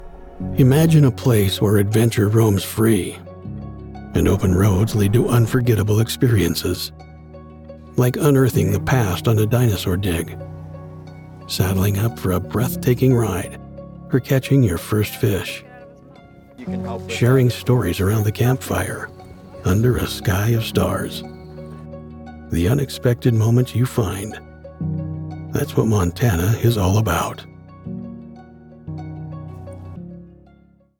The Mountain West has a sound — wide‑open, rugged, and shaped by real outdoor life.
• Clean, broadcast‑ready audio from a professional studio
Just a real voice with real grit.
Commercial Voice Over Demos